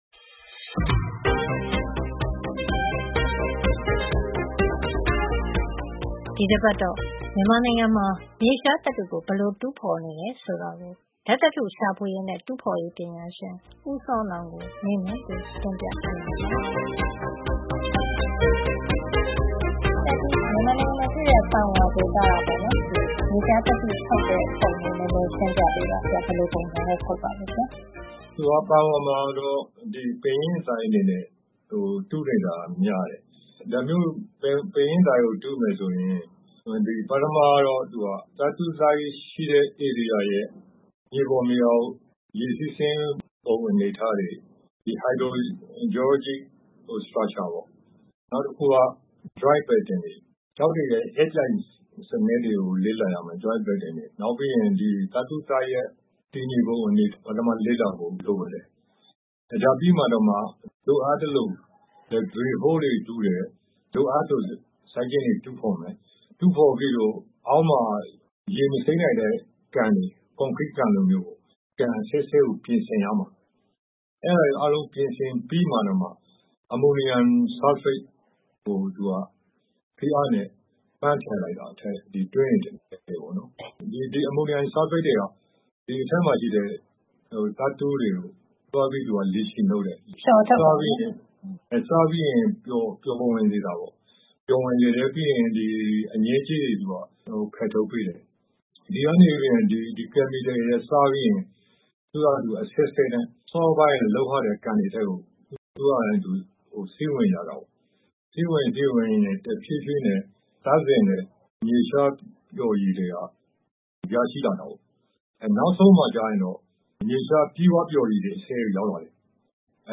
မေးမြန်းတင်ပြထားပါတယ်။